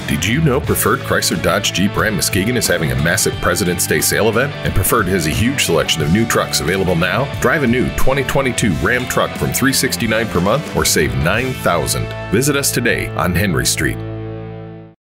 Middle Aged
Presidents Day Sale Event Commercial B  Preferred Chrysler Dodge Jeep Ram Muskegon_01.mp3